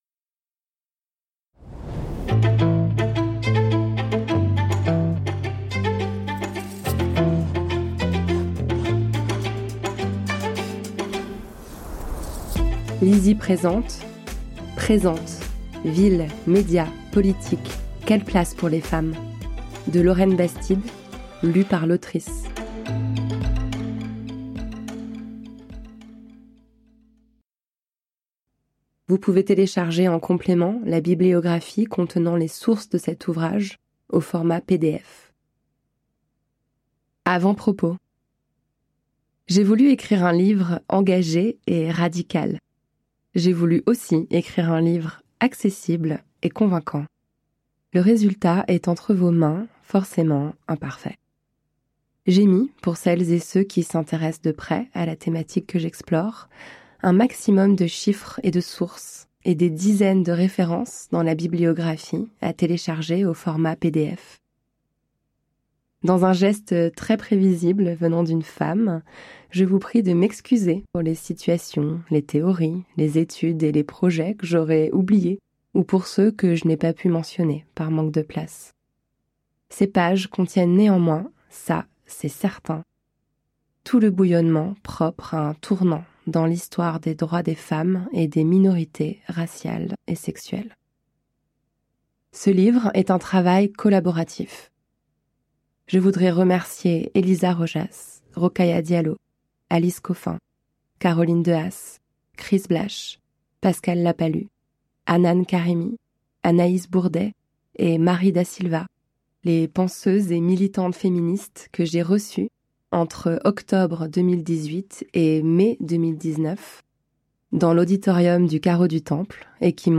je découvre un extrait - Présentes de Lauren Bastide